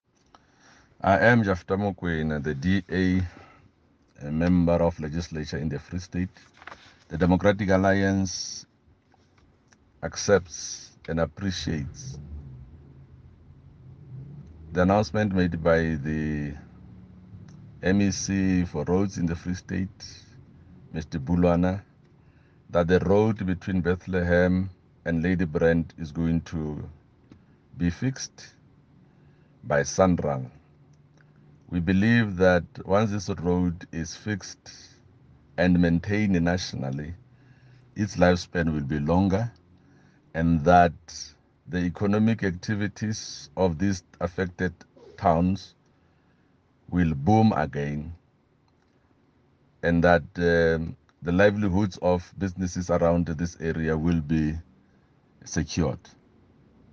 Issued by Jafta Mokoena – DA Roads and Transport Spokesperson in the Free State Legislature
English-soundbite.mp3